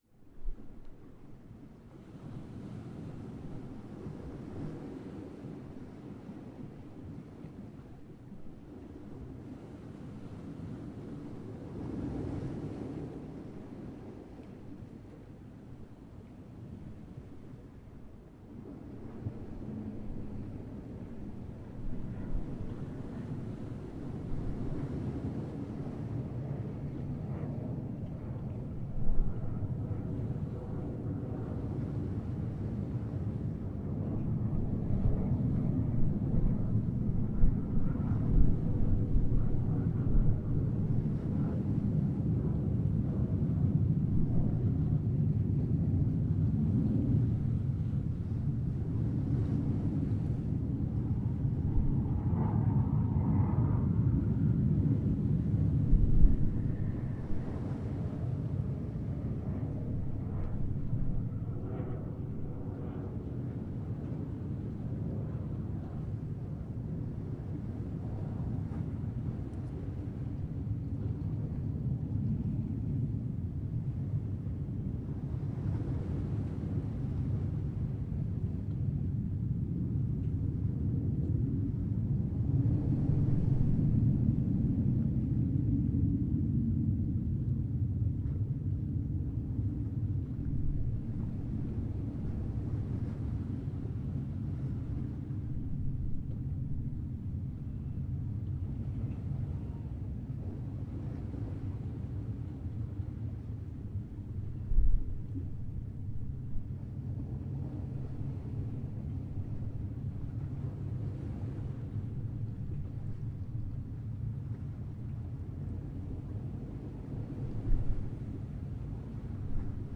吼猴丛林 " 吼猴丛林 1
描述：吼猴在墨西哥恰帕斯州波伦克的丛林里录制。在我的Zoom H4n上用内置的立体声电容话筒以24bit/96khz录制。对不起，我根本没有处理这些文件。其中有些是在90度XY配置下的麦克风，有些是在120度XY配置下的麦克风。
声道立体声